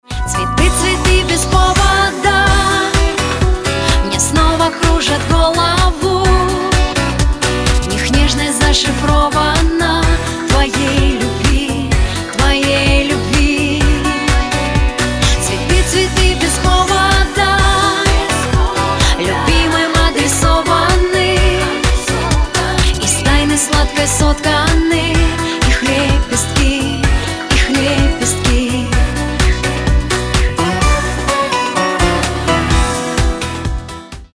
• Качество: 320, Stereo
красивые
женский вокал
романтичные
русский шансон